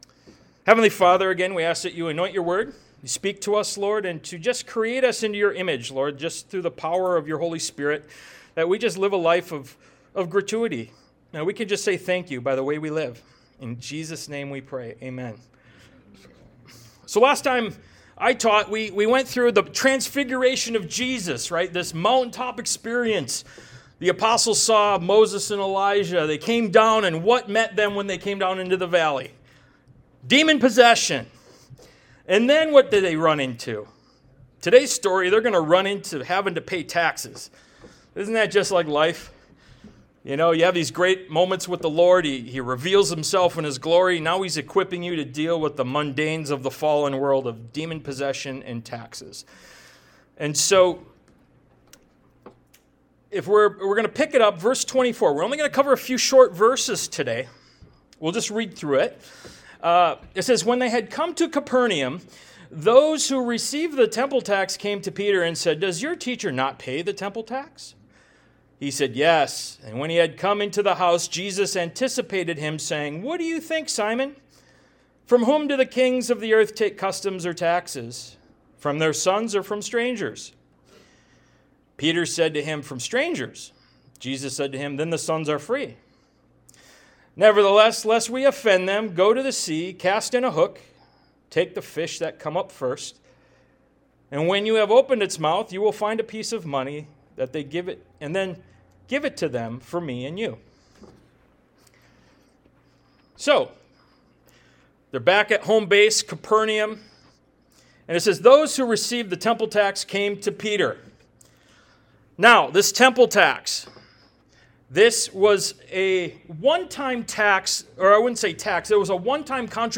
Ministry of Jesus Service Type: Sunday Morning « “Help My Unbelief” Ministry of Jesus Part 44 “Ephesians 1-14” »